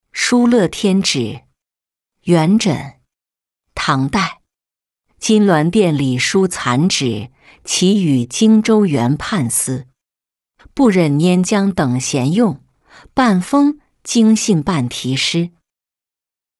书乐天纸-音频朗读